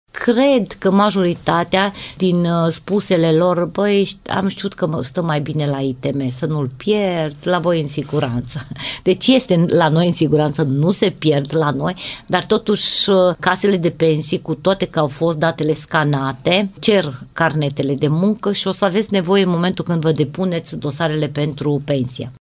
Inspectorul șef al ITM Timiș, Ileana Mogoșanu, reamintește că aceste carnete sunt necesare pentru a fi depuse la Casa de Pensii în momentul încheierii activității.